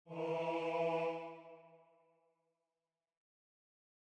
mi3_bajo.mp3